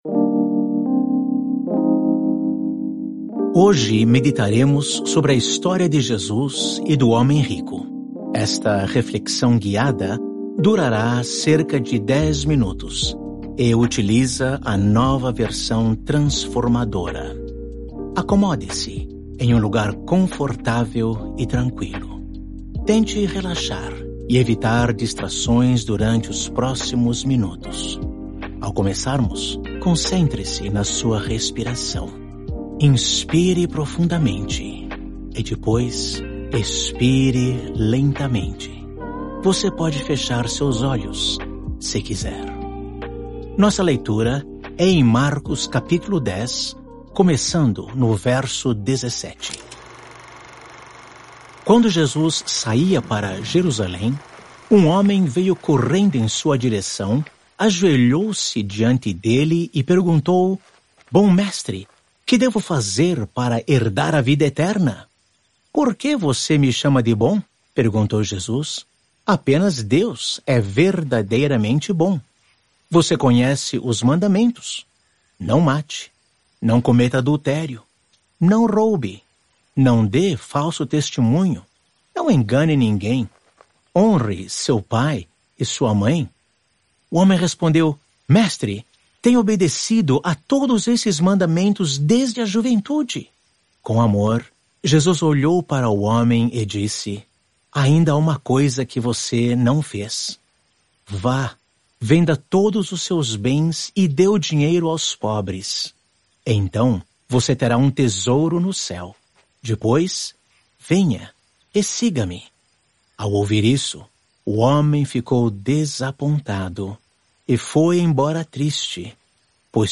Hoje, gostaríamos muito que se juntasse a nós nesta imersiva leitura da Bíblia.